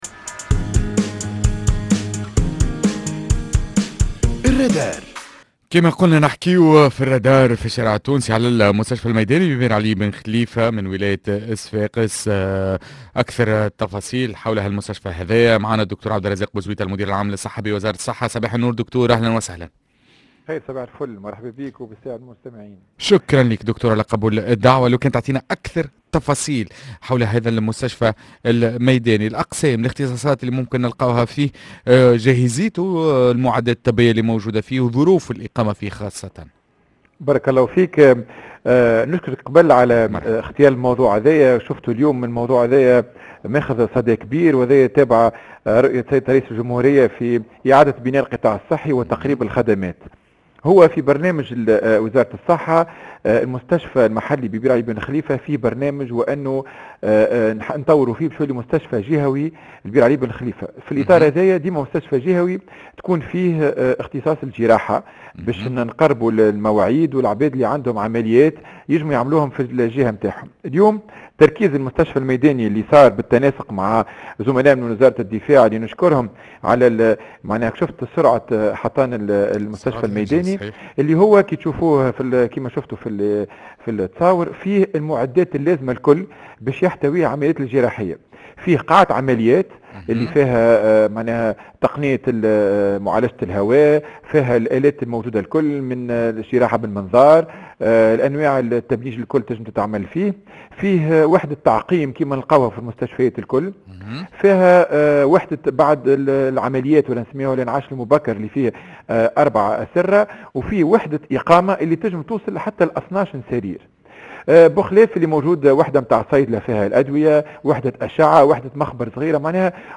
مع الدكتور عبد الرزاق بوزويتة المدير العام للصحة بوزارة الصحة